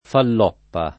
falloppa [ fall 0 ppa ] → faloppa